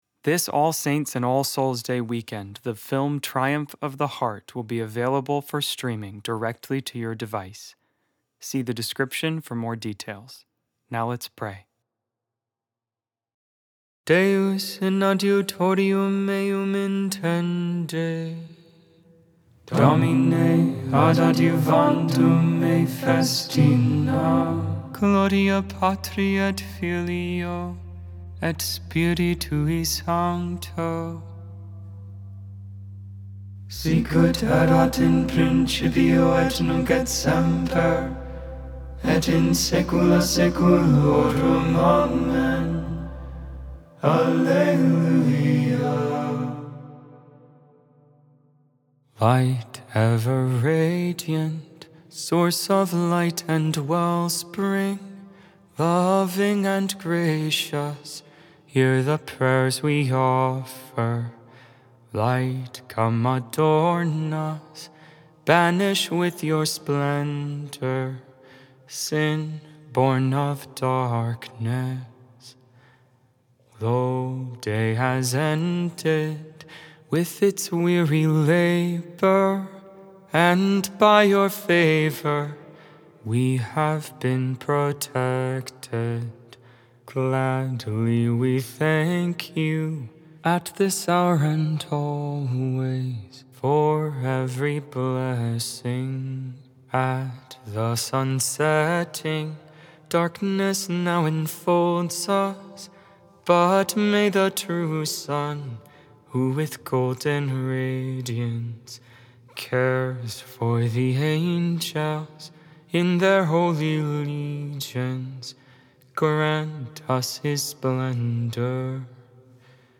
Vespers, Evening Prayer for the 30th Monday in Ordinary Time, October 27, 2025.Made without AI. 100% human vocals, 100% real prayer.